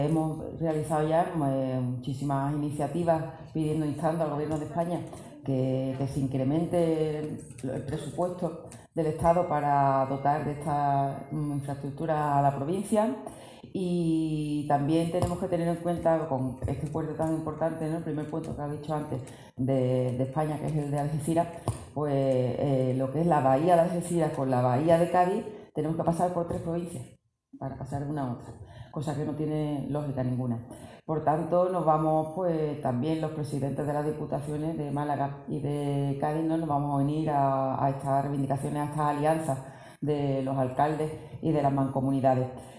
Martínez del Junco interviene en un foro organizado por Publicaciones del Sur en Estepona